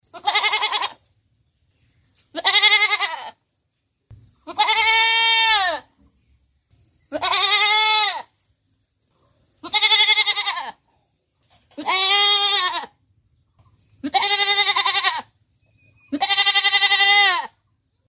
Звуки животных
Коза разговаривает